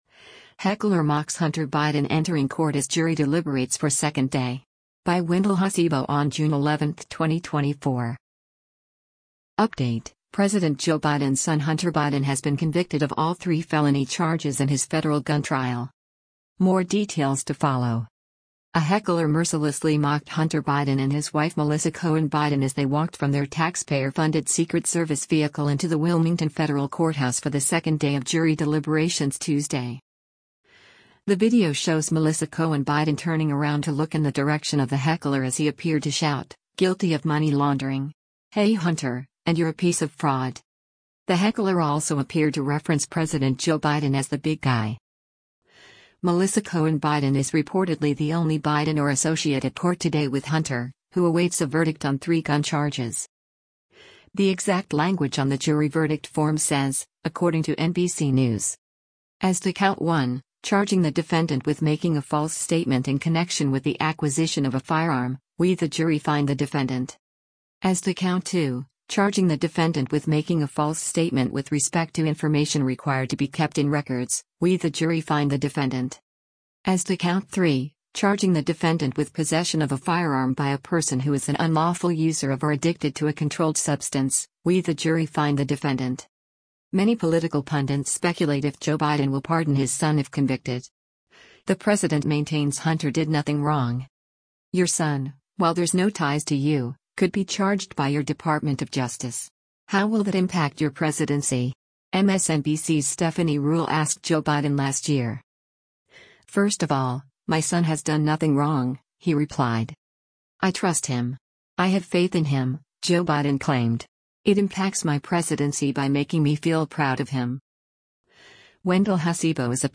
The video shows Melissa Cohen Biden turning around to look in the direction of the heckler as he appeared to shout, “Guilty of money laundering,” “Hey Hunter,” and “You’re a piece of fraud.”